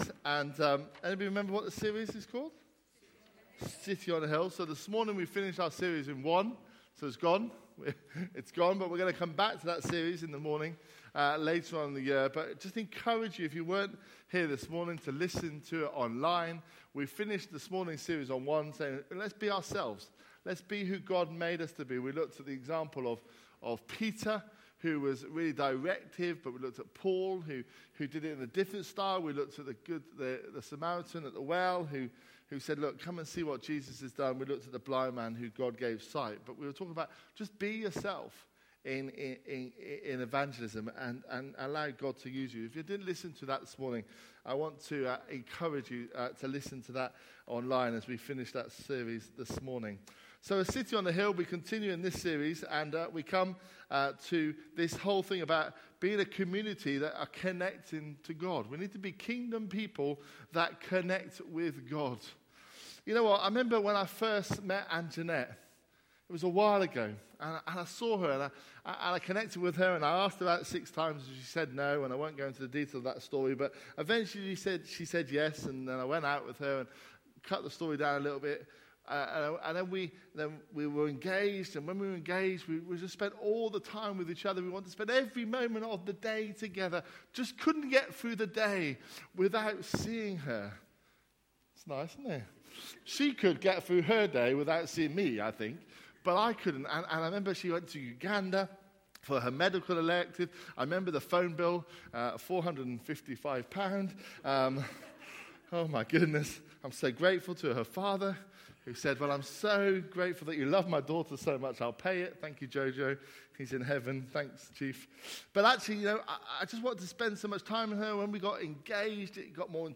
A message from the series "A City on a Hill."